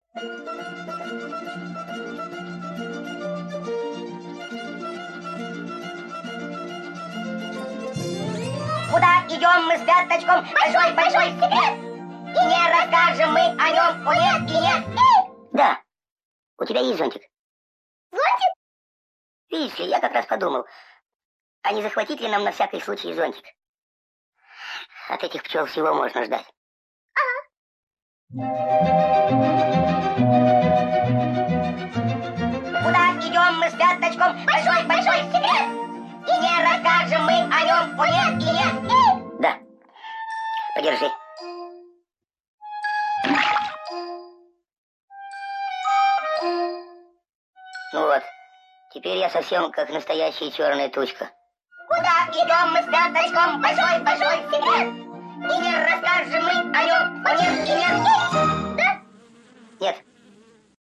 песня из мультфильма